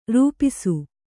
♪ rūp'su